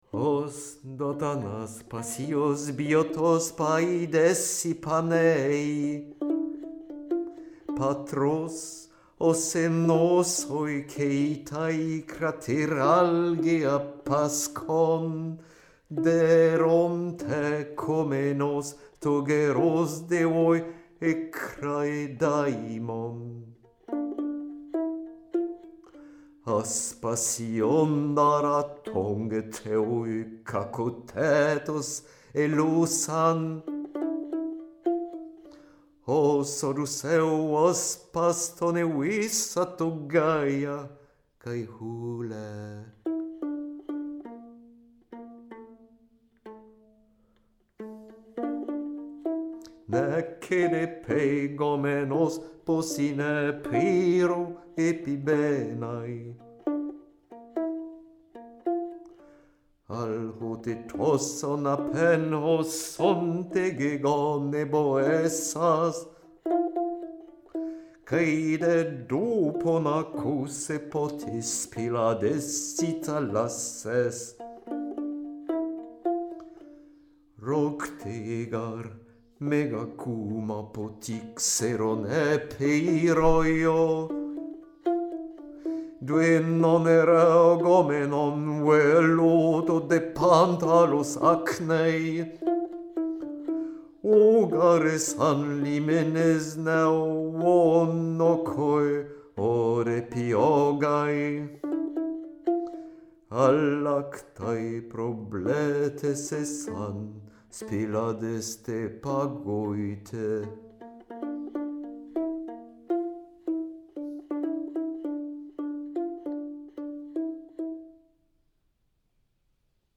Les traversées sont toujours chantées et accompagnées d'une lyre ancienne d'Egypte. Elles ont été toutes enregistrées dans le même espace acoustique (la salle François 1er) et dans le même rapport de distance aux micros.
La salle a un grand volume avec un sol en lames de chêne épaisses et disjointes, les murs sont en briques pleines. La résonance de la salle est claire, la cheminée offerte par François 1er en 1514 est ouverte au jour et filtre ainsi que les ouvertures en verres et vitraux, les bruits extérieurs. Les oiseaux sont une présence lointaine quazi constante pendant la durée des enregistrements des Traversées.
Couple variable Neumann KM143, angle 40°, distance entre capsules= 20cm, Distance avec la voix = 70cm